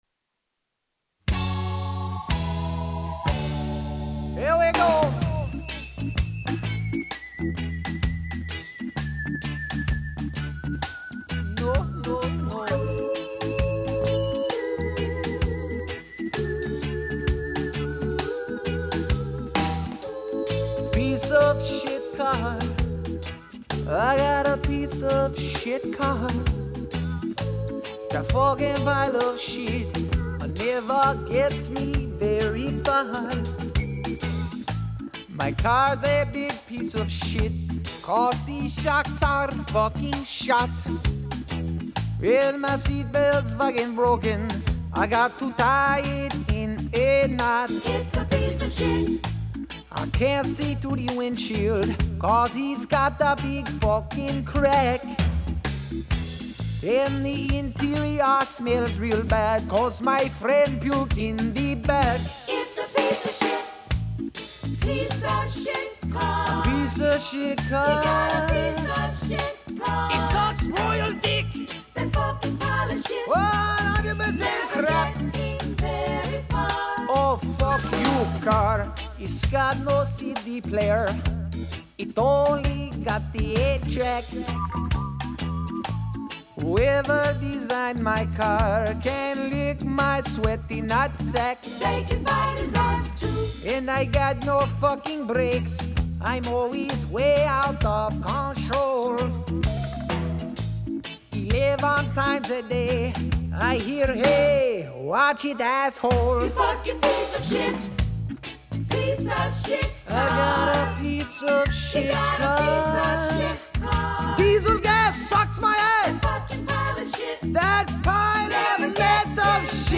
Tags: Funny Comedy Rock Music Cool adult